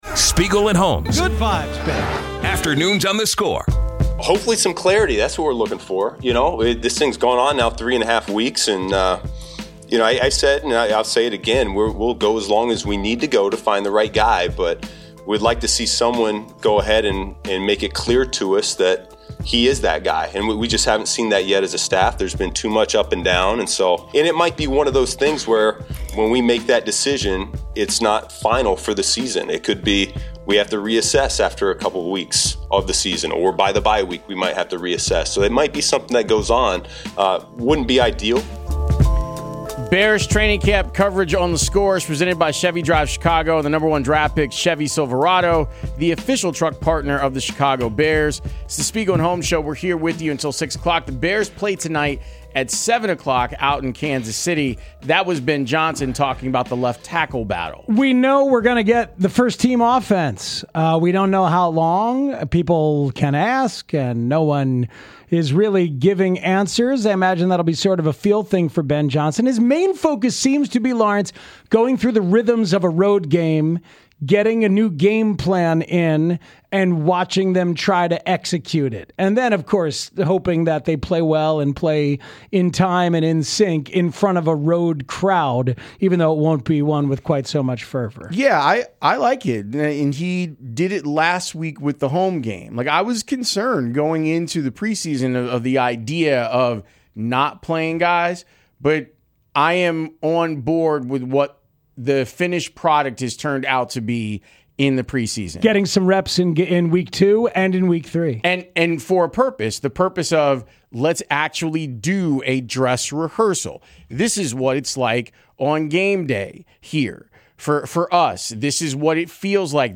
Eddy Curry is in town for Bulls Fest this weekend and stops by the show (Hour 3)